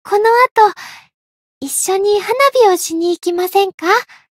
灵魂潮汐-伊汐尔-春节（摸头语音）.ogg